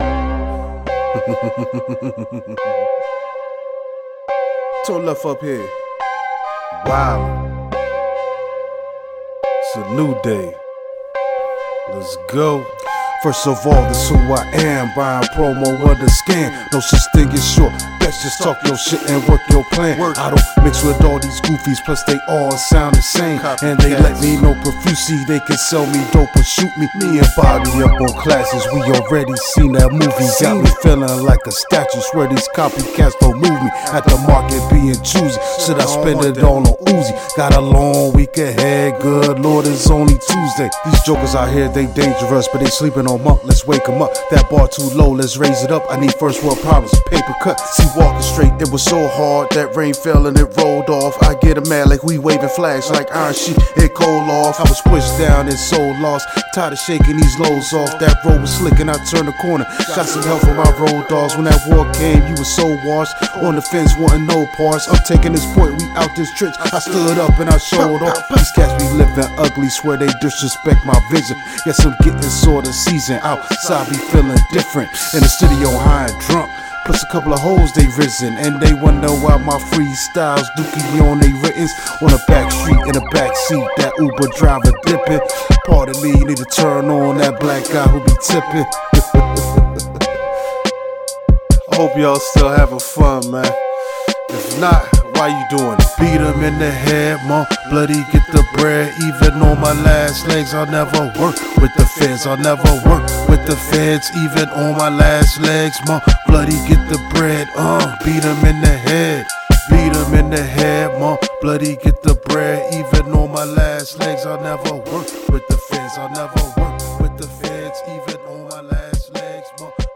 Head banger!!